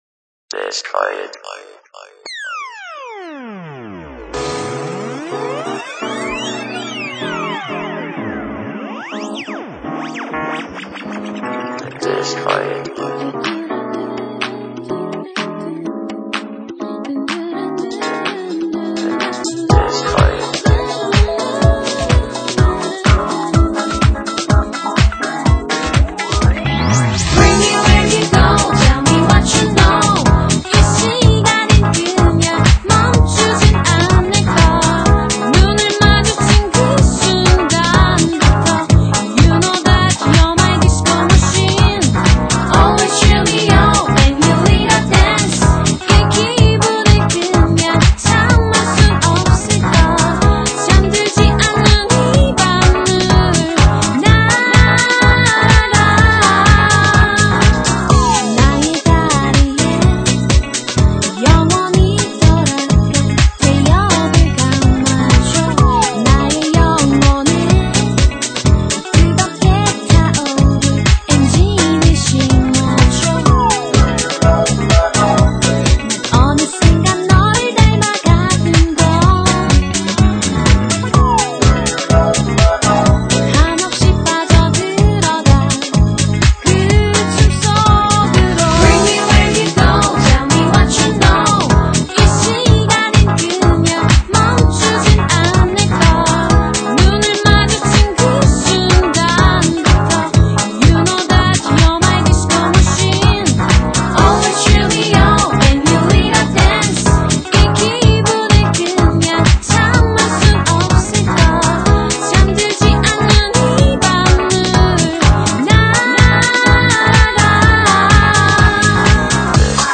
간만에 듣는 에시드 국산도 에시드 수준이 참 높아 졌다....
일본 시부야 냄새도 나는구려..... 하여튼 비 주류 음악을 한다는 것만으로도.....